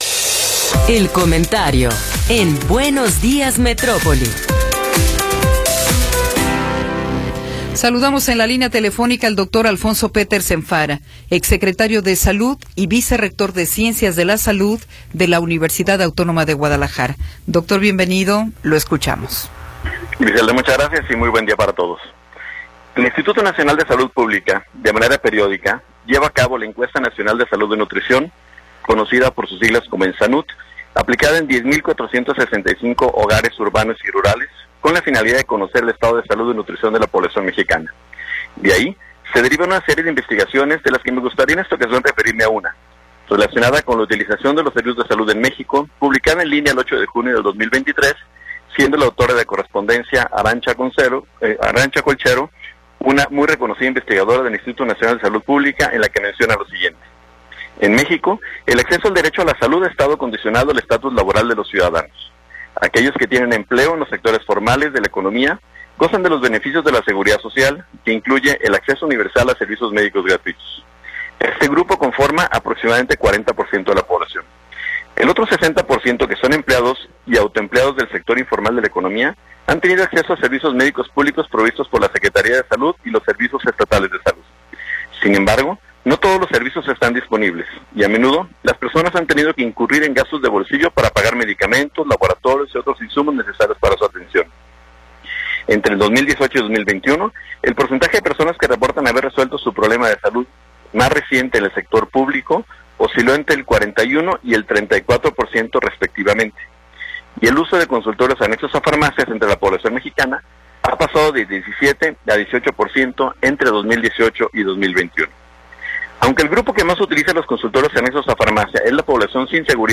Comentario de Alfonso Petersen Farah
El Dr. Alfonso Petersen Farah, vicerrector de ciencias de la salud de la UAG y exsecretario de salud del estado de Jalisco, nos habla sobre la Encuesta Nacional de Salud y Nutrición y la utilización de los servicios de salud en México.